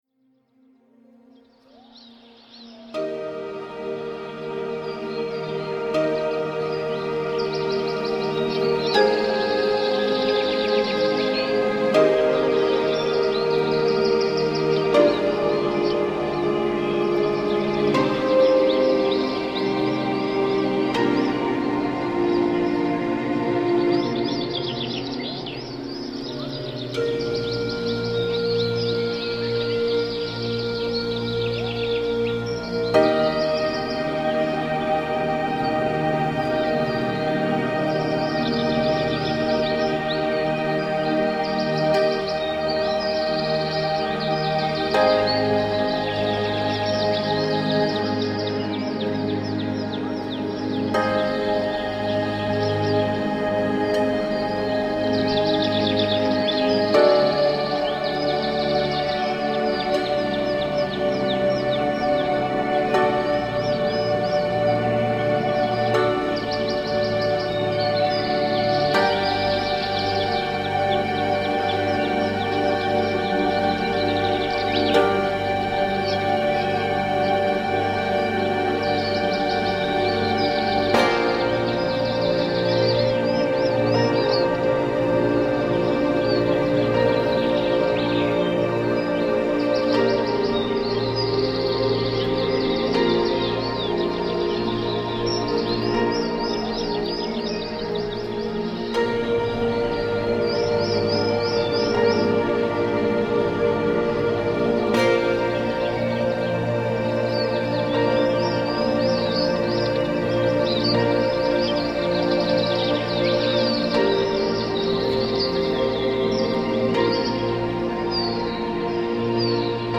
Собака звонит в Apple, телефон с игрой звуковых эффектов и уникальным звонком